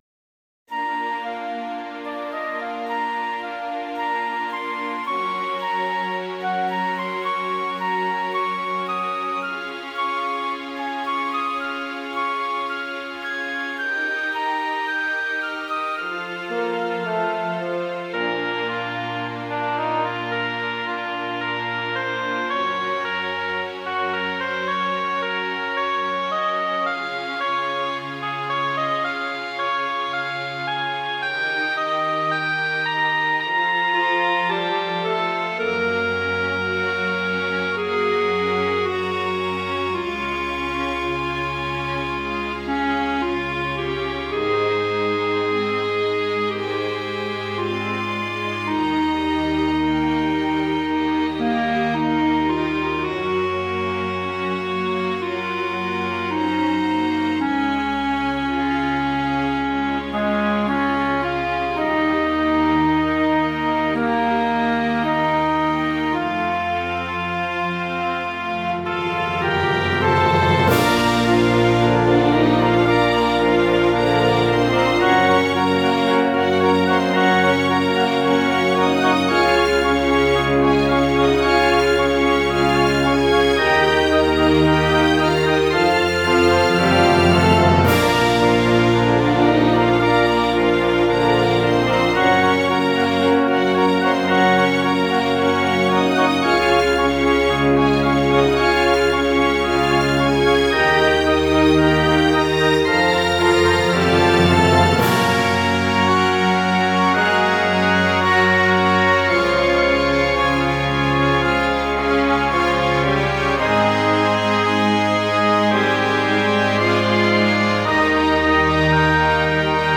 ogg(L) 爽やか オーケストラ ゆったり
静かな始まりから美しく盛り上がるオーケストラ。